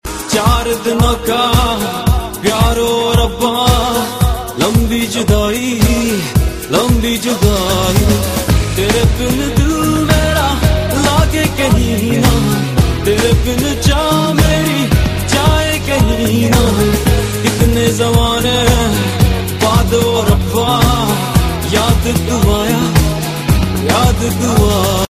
Bollywood & Indian